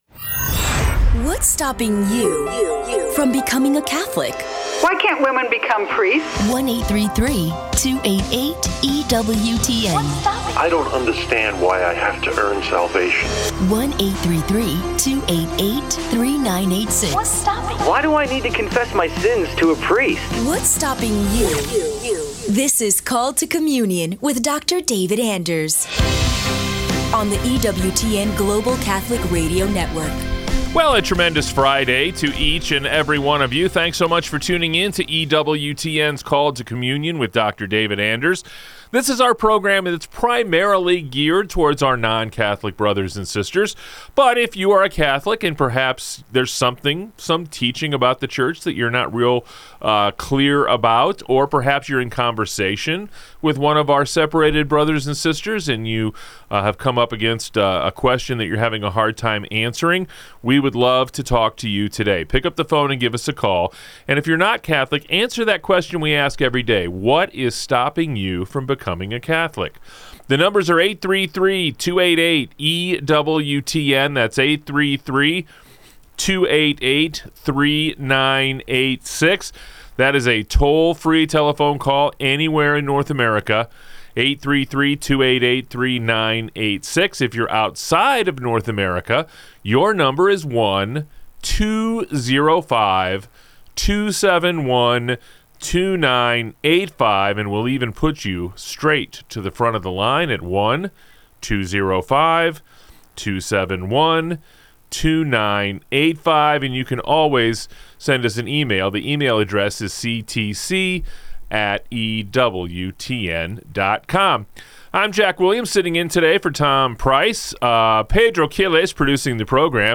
public ios_share Called to Communion chevron_right Seeking Truth, Goodness and Beauty Mar 6, 2026 A lively discussion about whether modern culture truly embraces moral relativism and how objective truth can be defended.